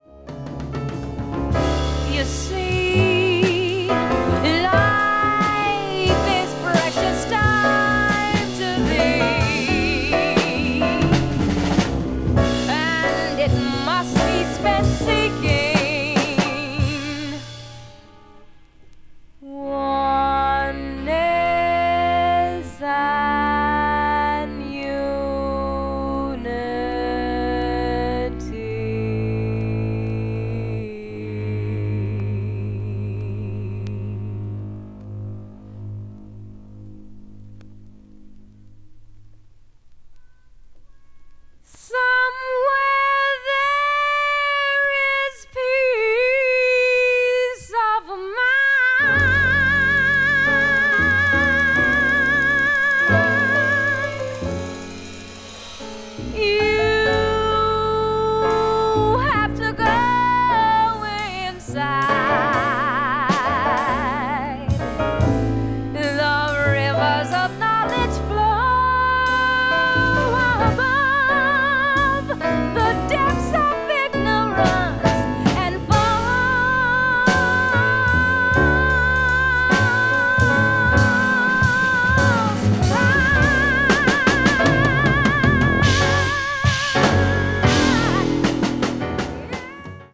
media : EX-/EX(わずかにチリノイズが入る箇所あり,A:再生音に影響ない薄いスリキズ2本あり)